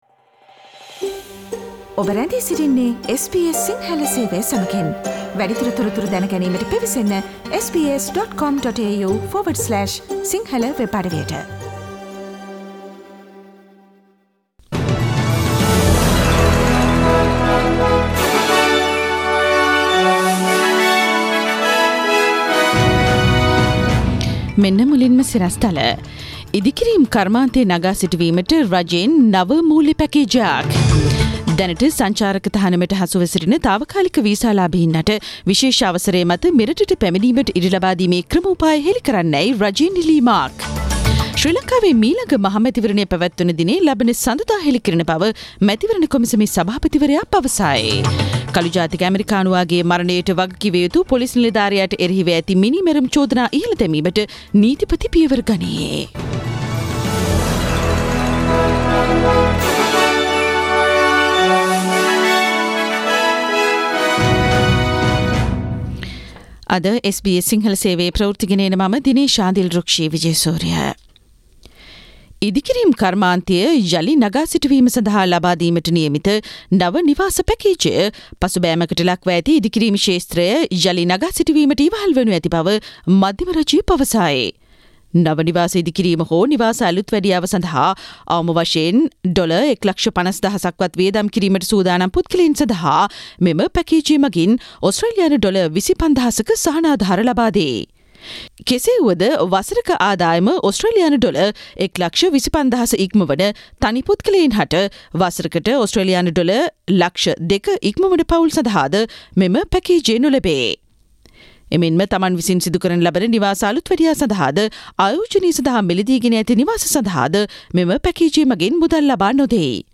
Daily News bulletin of SBS Sinhala Service: Thursday 04 June 2020
Today’s news bulletin of SBS Sinhala radio – Thursday 04 June 2020.